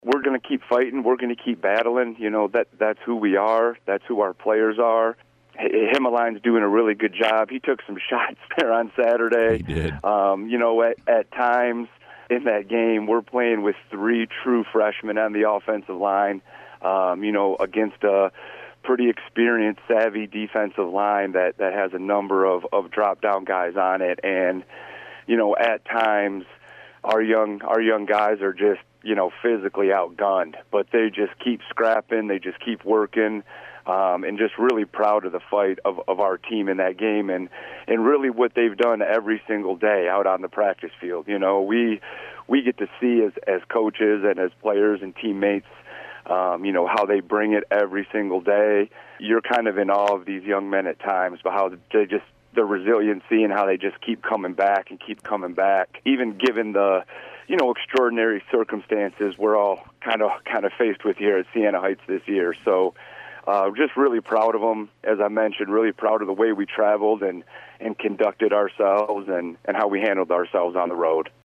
was a recent guest on 96.5fm The Cave